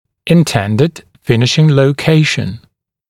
[ɪn’tendɪd ‘fɪnɪʃɪŋ ləu’keɪʃn][ин’тэндид ‘финишин лоу’кейшн]задуманное положение (зуба) в конце лечения, целевое положение зуба